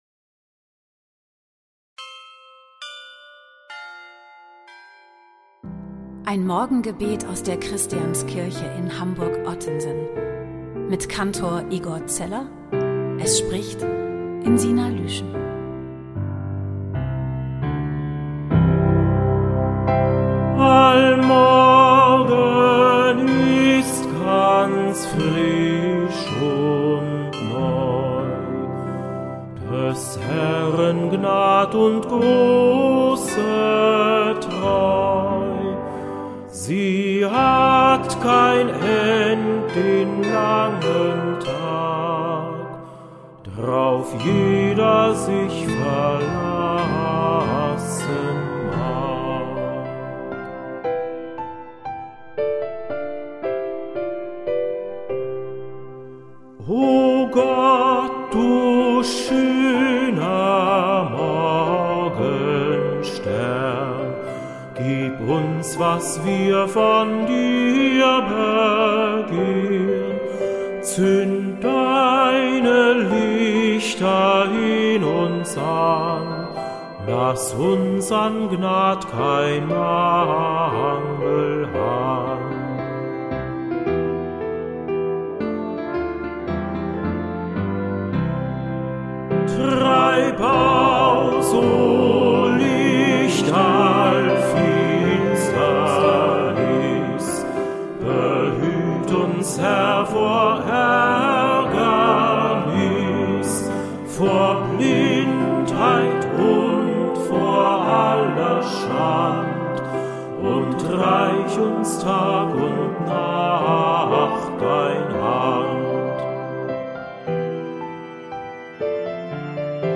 Morgengebet aus der Christianskirche Hamburg-Ottensen
Gebete, Texte und Gesänge aus der Christianskirche